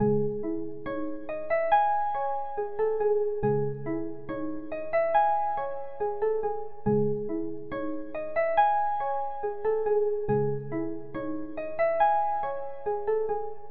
EN - Pharaoh (140 BPM).wav